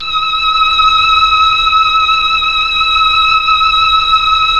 Index of /90_sSampleCDs/Roland LCDP09 Keys of the 60s and 70s 1/STR_Melo.Strings/STR_Tron Strings